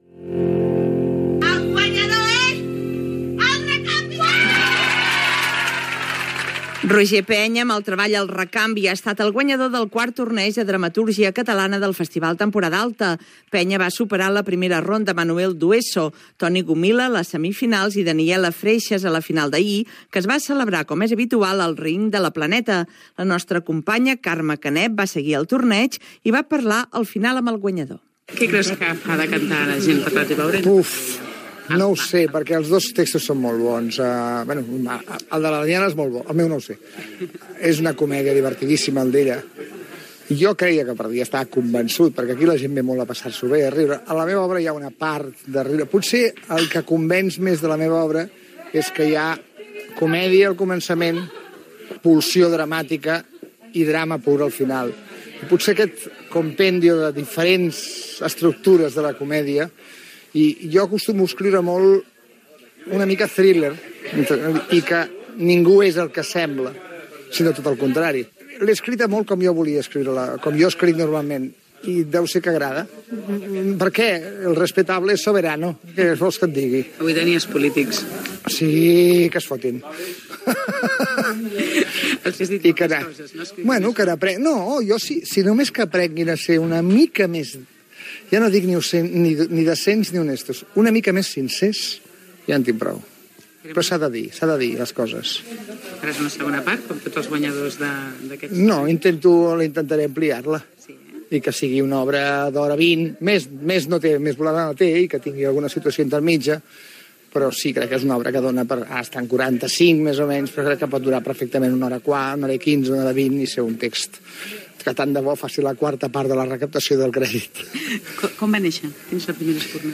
Cultura